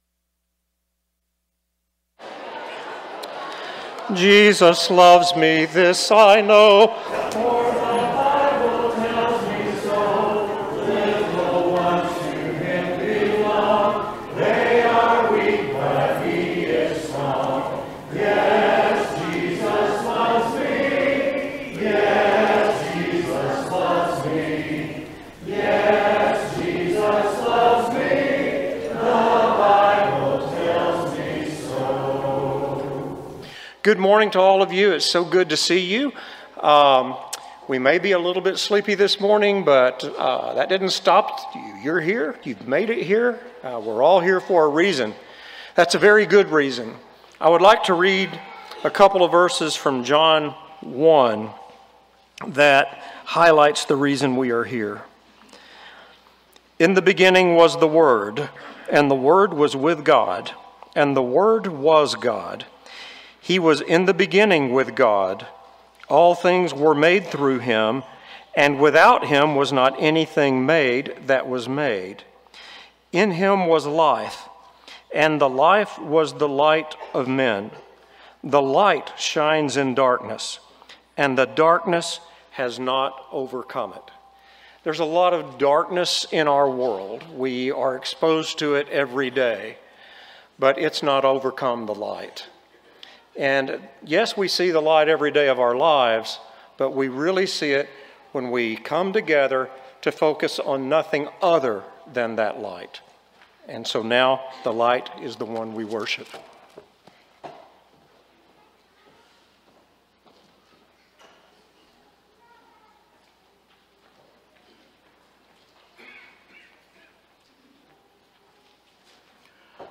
Matthew 7:14, English Standard Version Series: Sunday AM Service